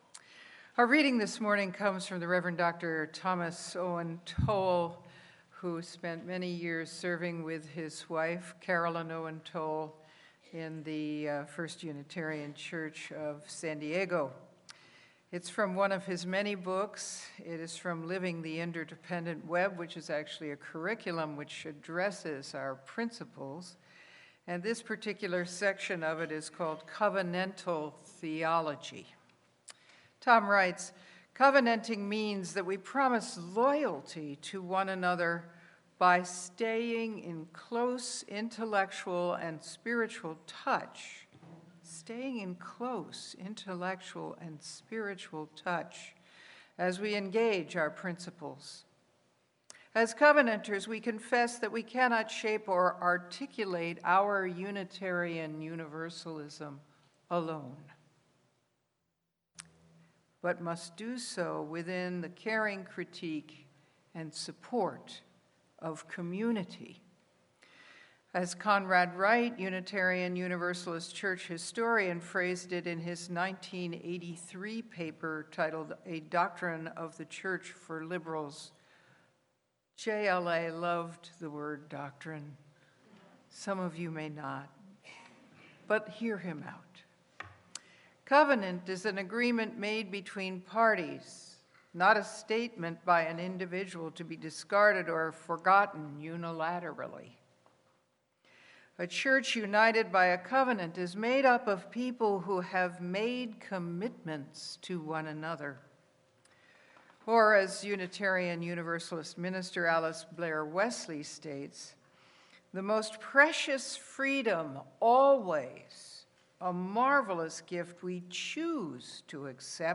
Sermon-The-Spiritual-Practice-of-Covenant.mp3